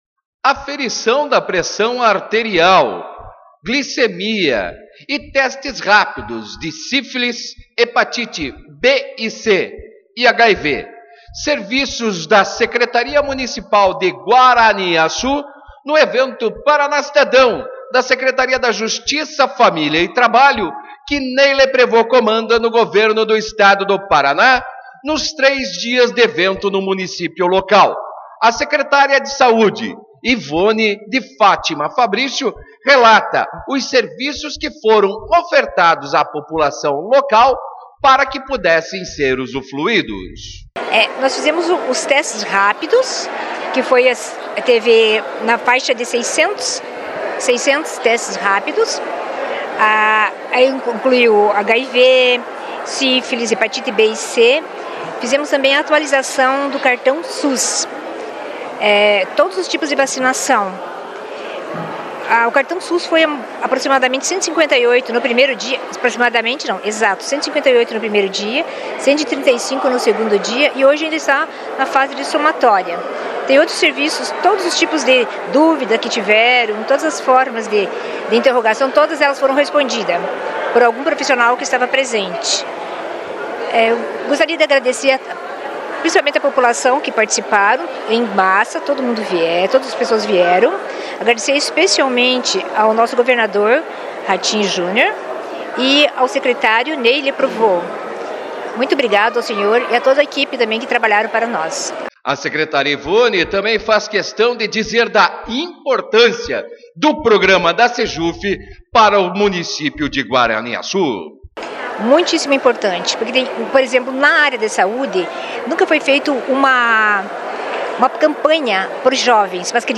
Secretária Municipal de Saúde de Guaraniaçu fala sobre o Paraná Cidadão em sua cidade